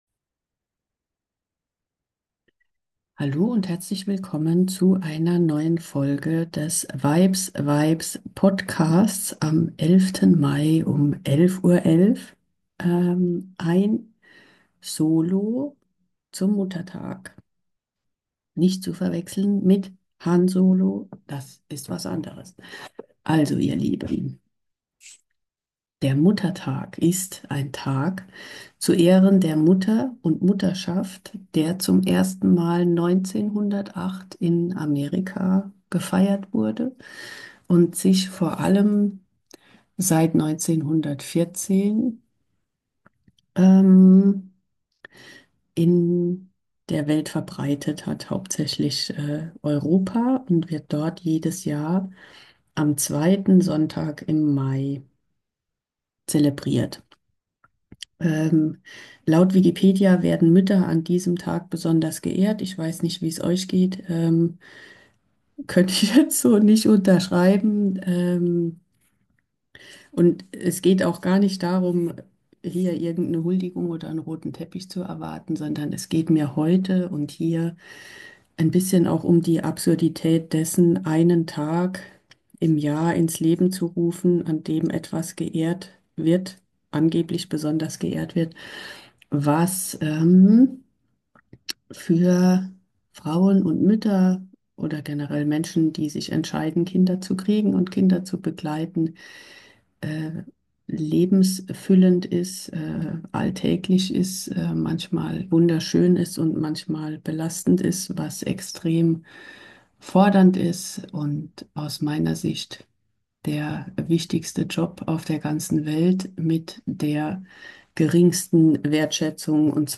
Es ist mal wieder Zeit für ein Solo.